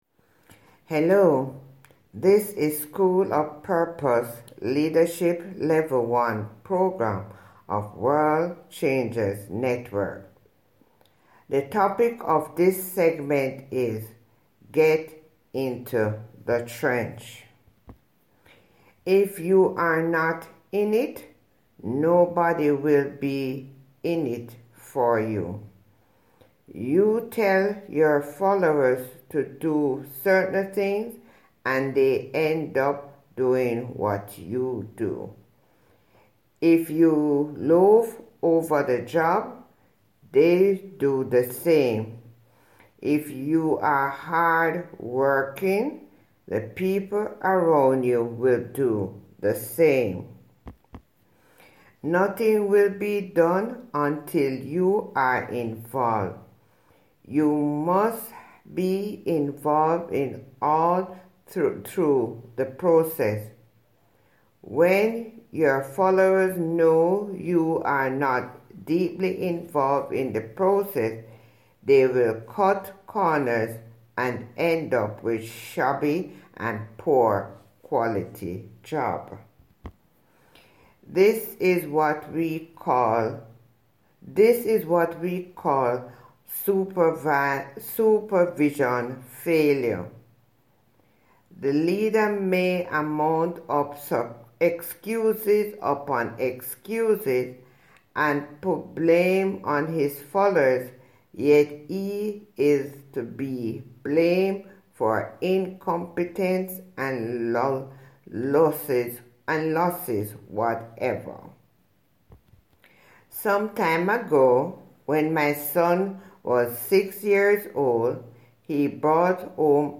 Listen to Human Voice Reading Here: